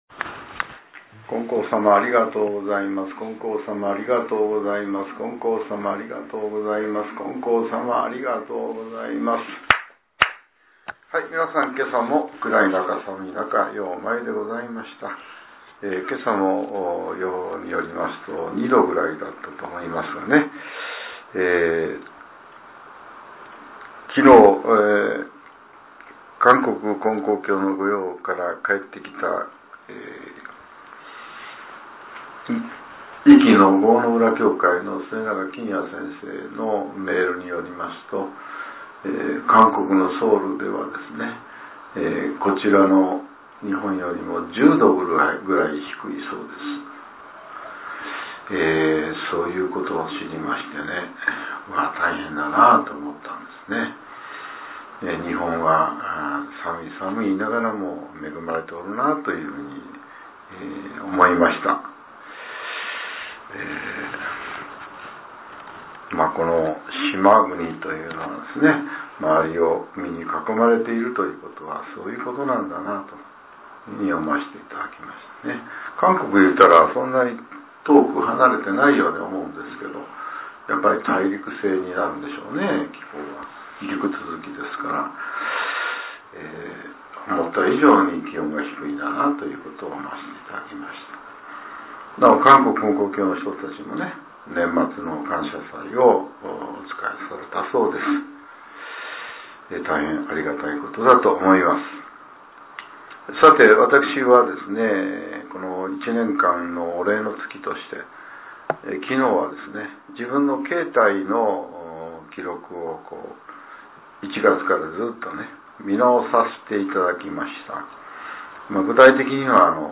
令和６年１２月１８日（朝）のお話が、音声ブログとして更新されています。